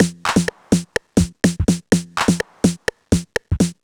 cch_percussion_loop_behind_125.wav